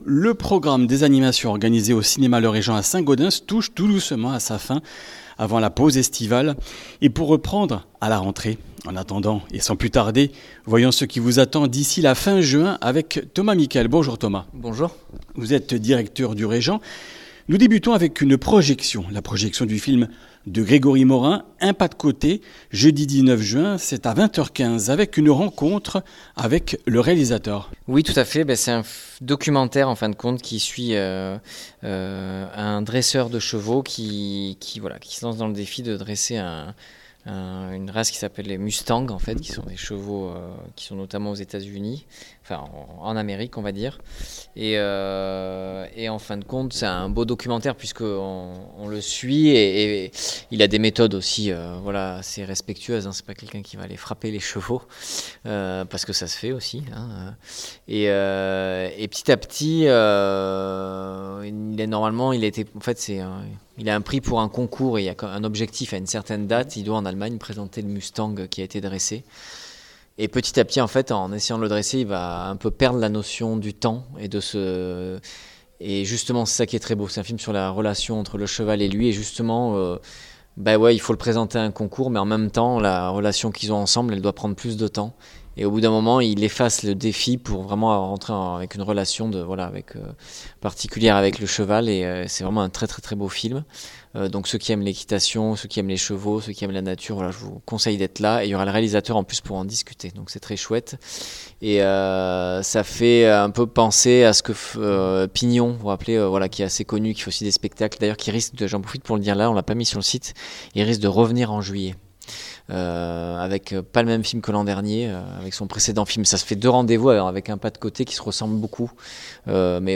Comminges Interviews du 12 juin